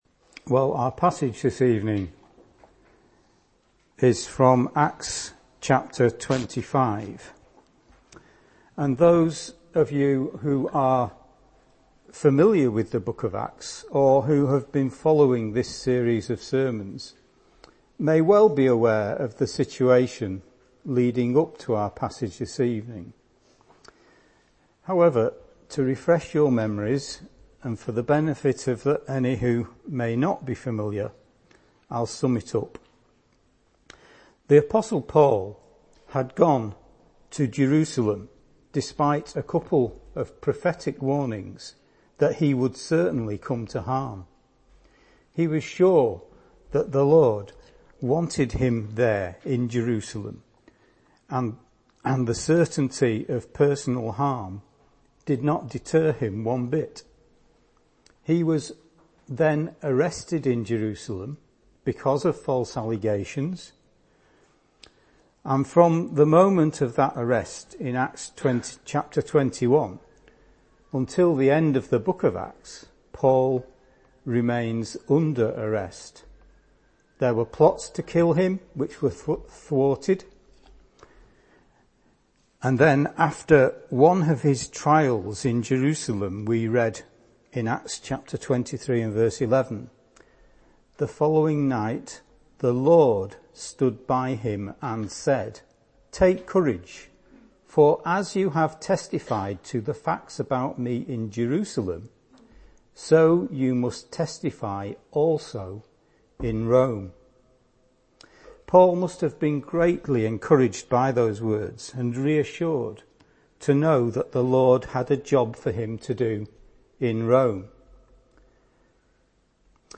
Sermon Podcasts Downloads | Salem Chapel, Martin Top